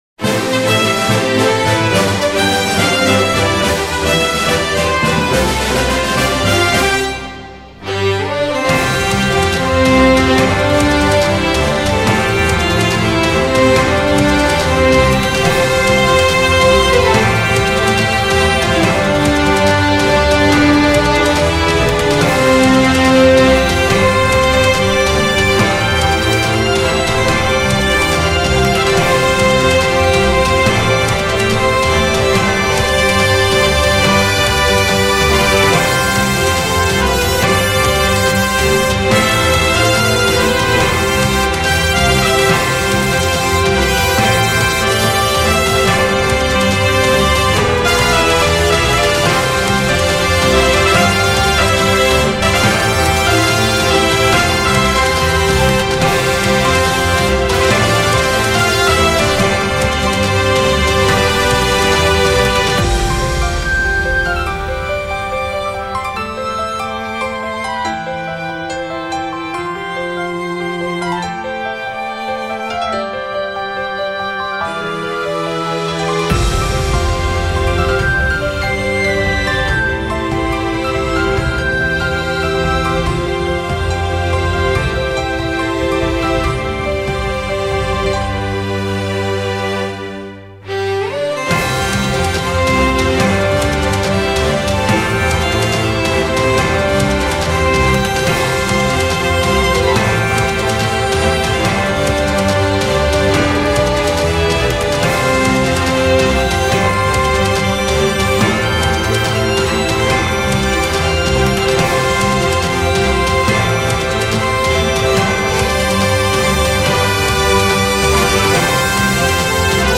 オーケストラ/室内楽(Orchestra/chamber)
ジャンルクラシック
BPM１４２
使用楽器ヴァイオリン、トランペット、フルート、ピアノ
解説動画やゲームのオープニング曲を意識したオーケストラのBGMです。